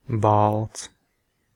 Ääntäminen
Ääntäminen Tuntematon aksentti: IPA: [bālts] Haettu sana löytyi näillä lähdekielillä: latvia Käännös Ääninäyte Substantiivit 1.